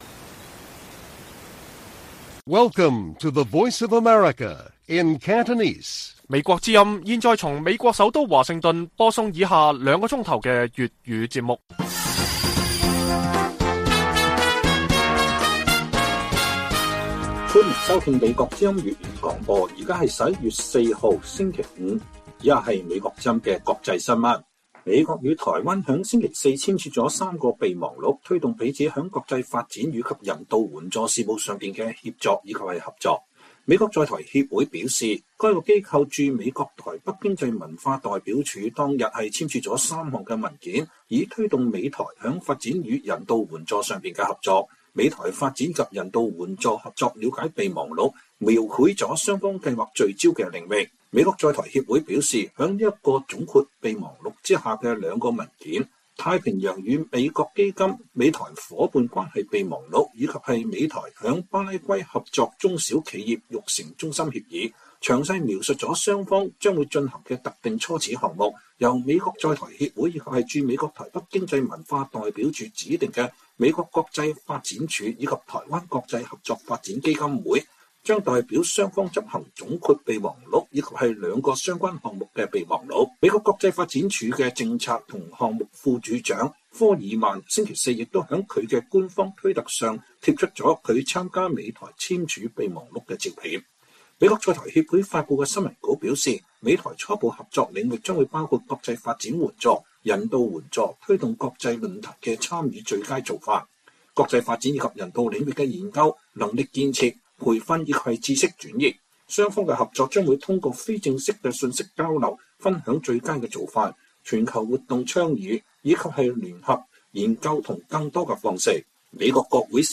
粵語新聞 晚上9-10點: 美台簽備忘錄推動國際發展與人道援助合作